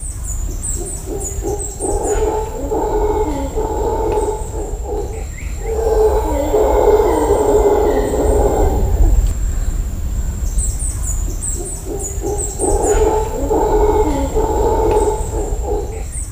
HowlerMonkey.ogg